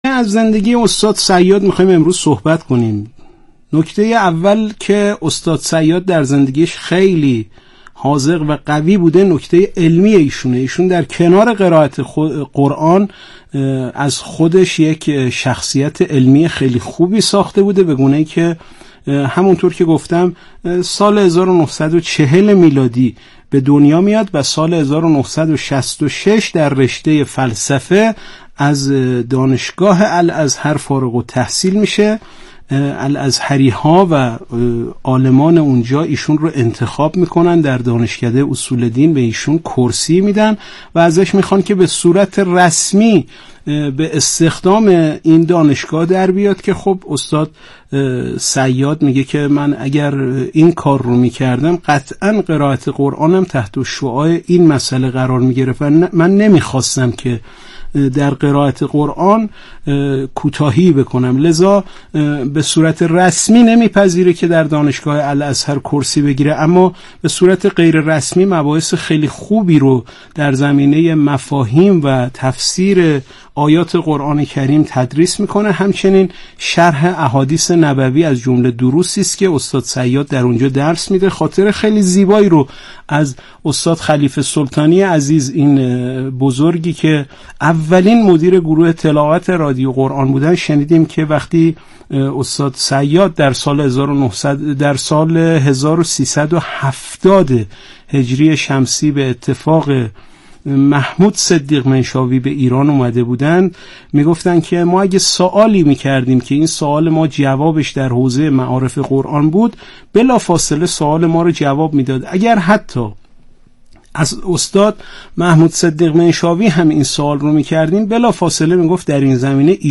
صوت کارشناسی
برنامه رادیویی اکسیر